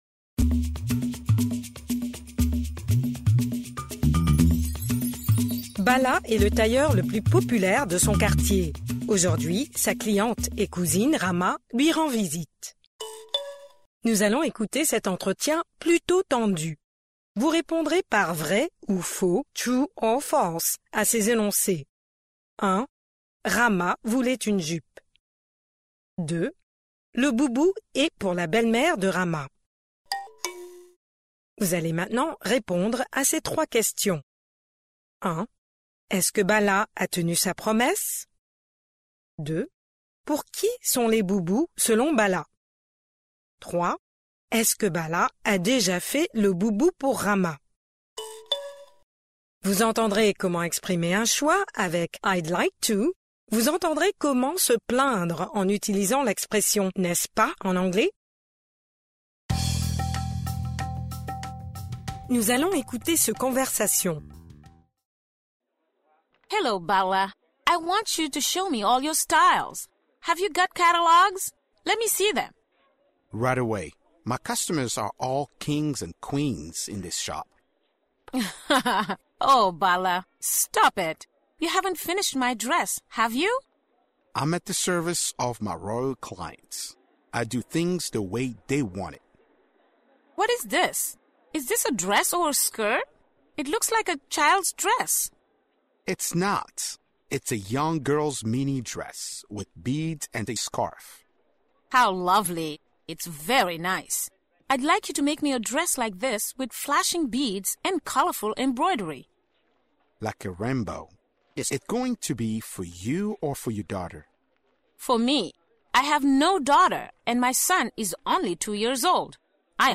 Pre-listening: Nous allons écouter cet entretien, plutôt tendu !